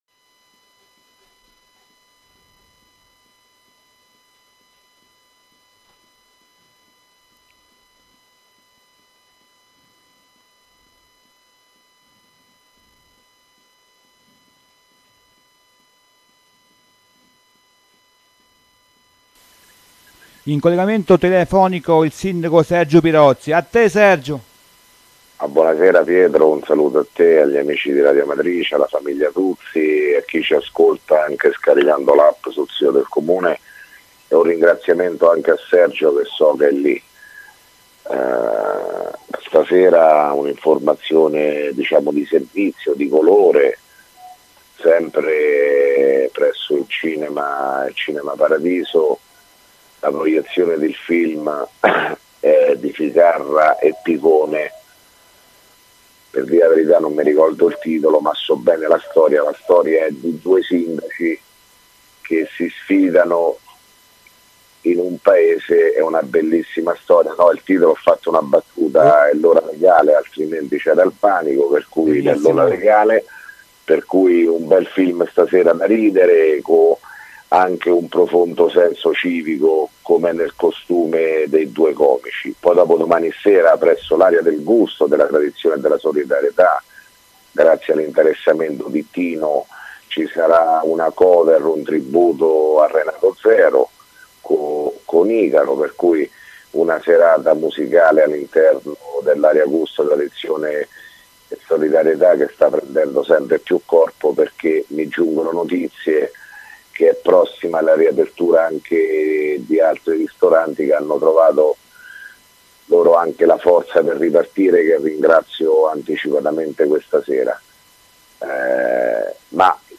Di seguito il messaggio audio del Sindaco Sergio Pirozzi del 12 agosto 2017